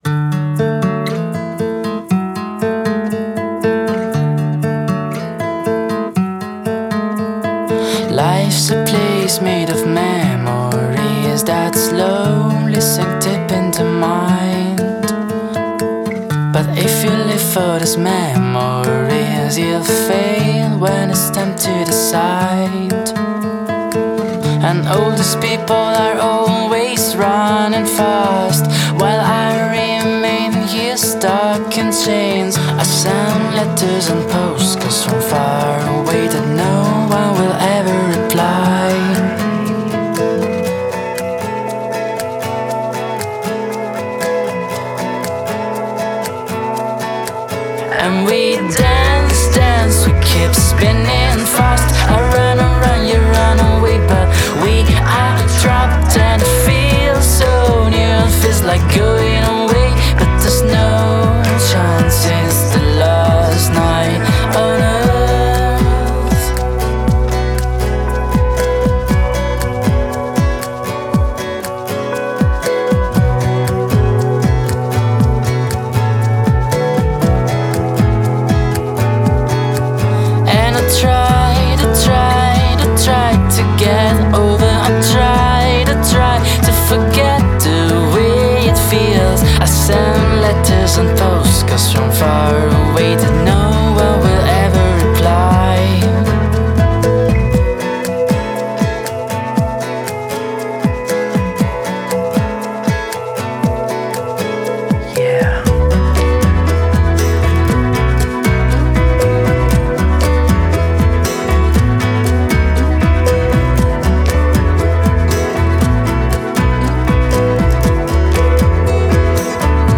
Band alternative rock italo/francese con base a Roma